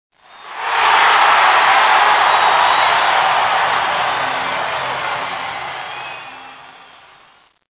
c_cheer1.mp3